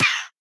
assets / minecraft / sounds / mob / parrot / death4.ogg